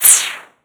woosh_a.wav